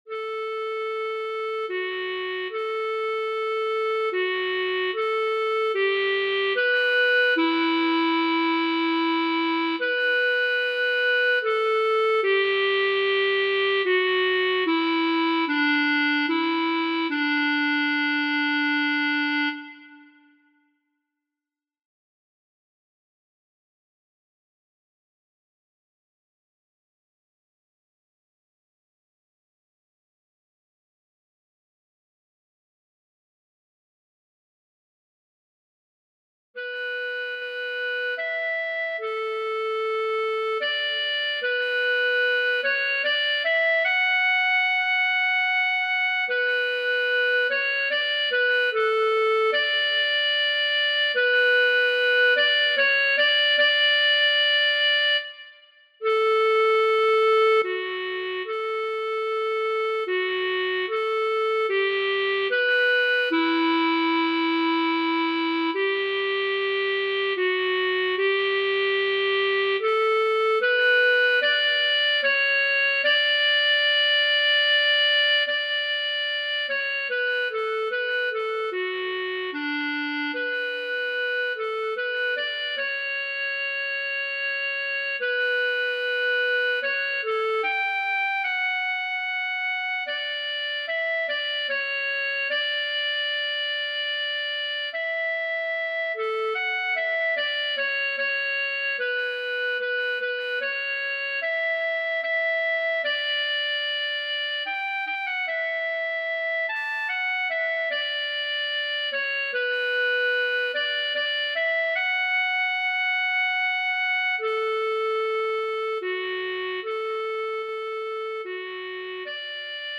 B-flat clarinet obbligato